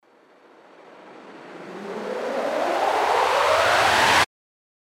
FX-1536-RISER
FX-1536-RISER.mp3